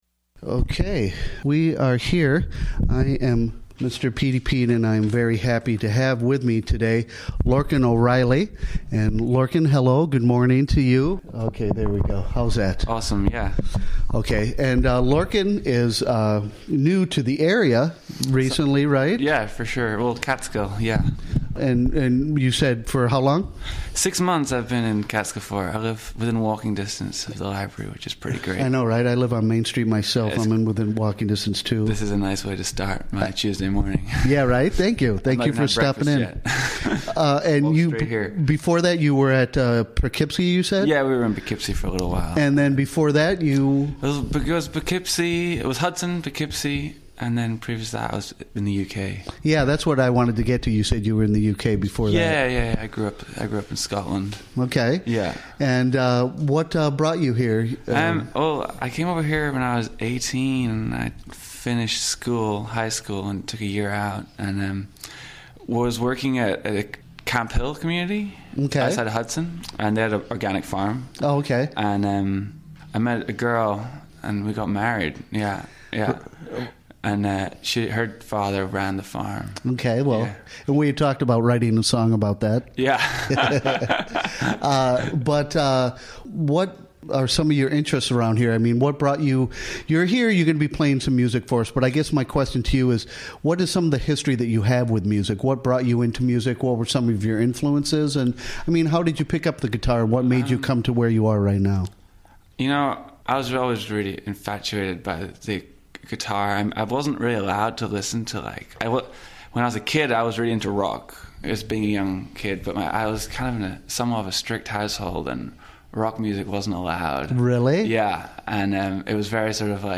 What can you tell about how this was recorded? Recorded during the WGXC Morning Show of Tuesday, Feb. 13, 2018.